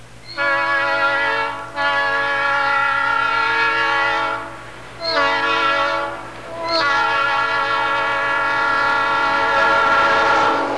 The P3 was first produced in 1952 as a derivative of the P5 (P bells 1, 2, and 4 from the P5). As with the P5, the castings changed over time to produce slight variations in sound.